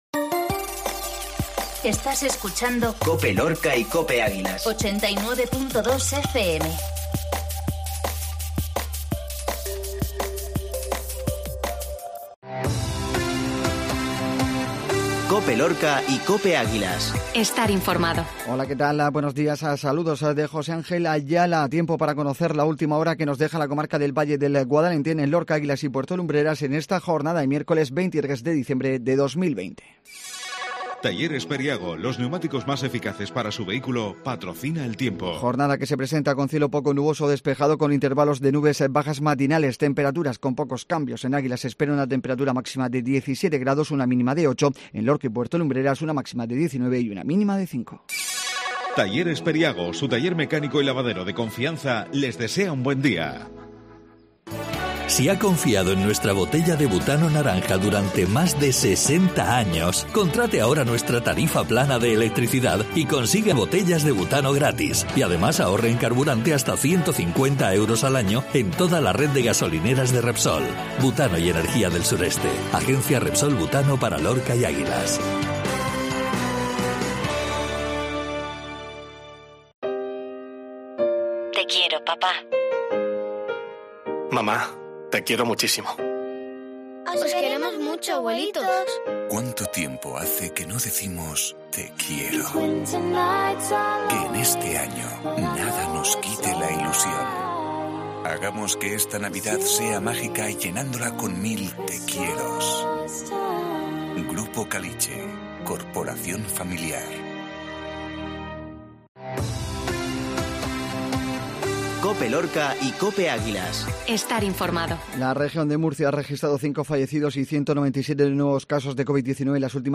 INFORMATIVO MATINAL MIÉRCOLES 2312